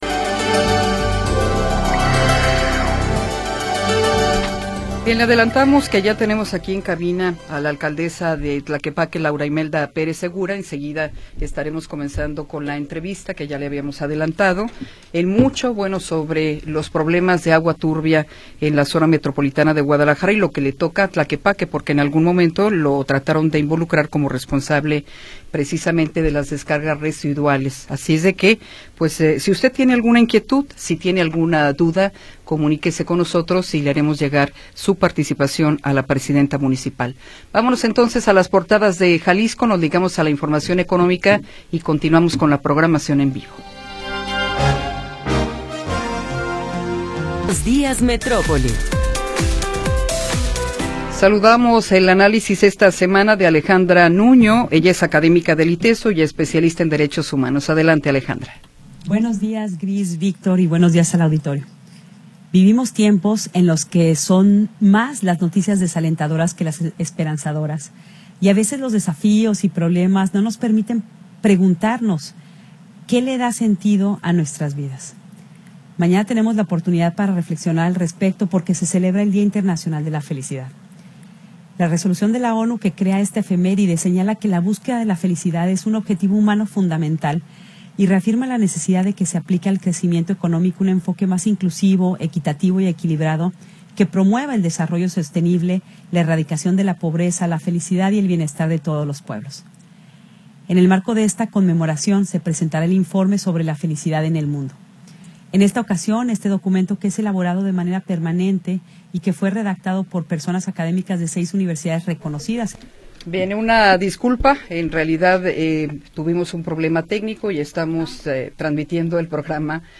Tercera hora del programa transmitido el 26 de Marzo de 2026.